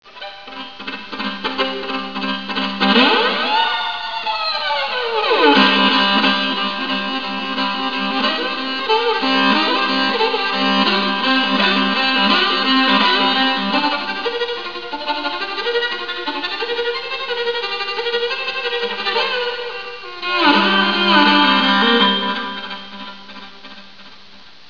馬頭琴の魅力たっぷりのCDです。